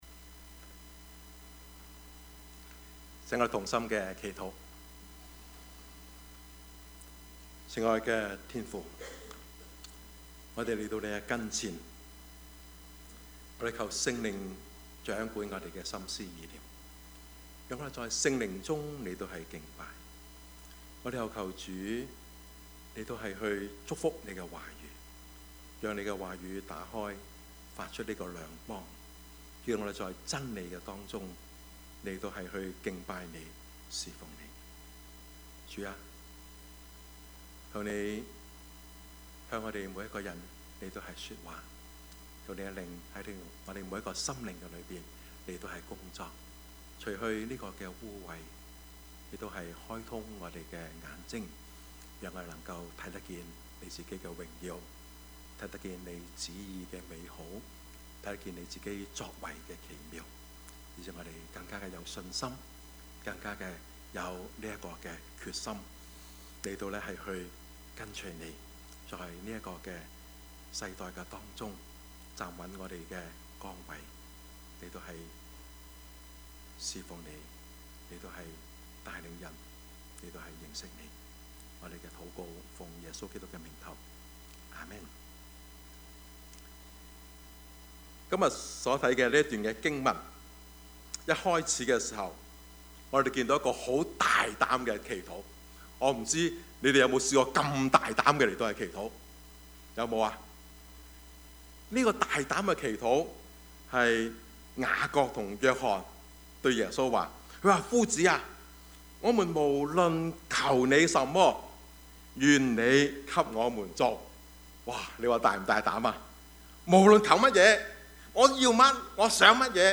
Service Type: 主日崇拜
Topics: 主日證道 « 政教分離?